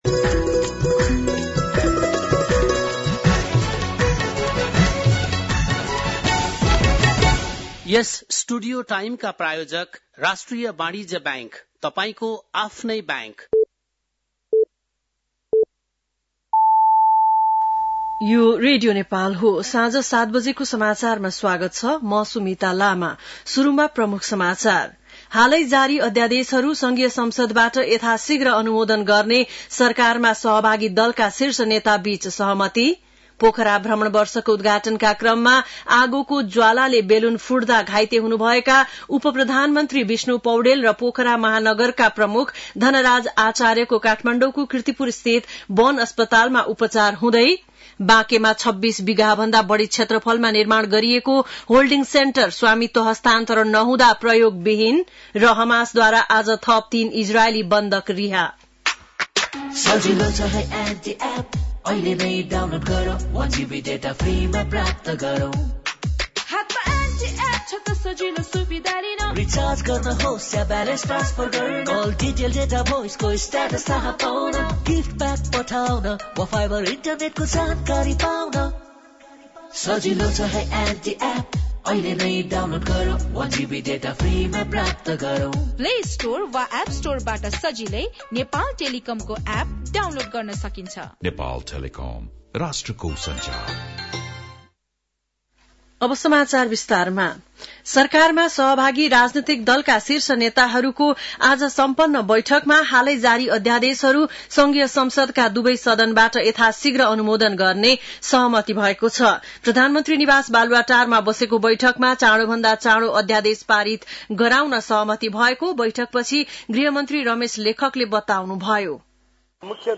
बेलुकी ७ बजेको नेपाली समाचार : ४ फागुन , २०८१